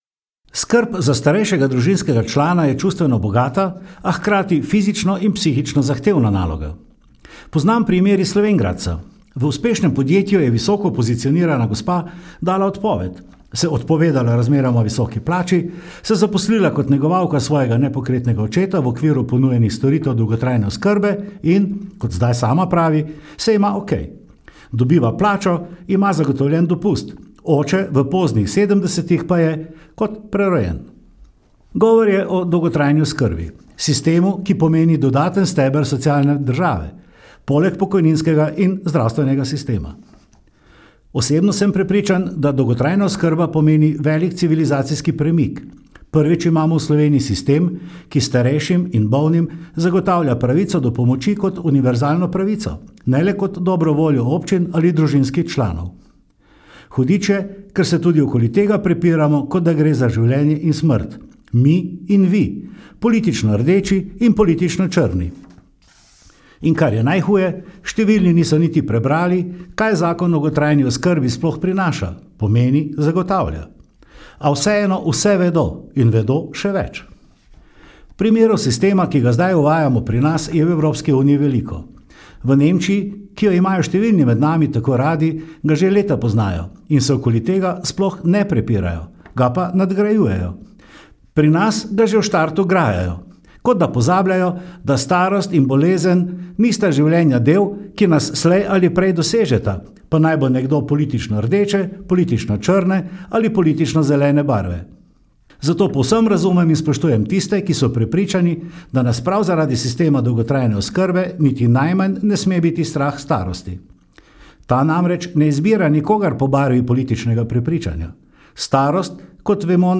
Oznake: starejšikomentaroskrba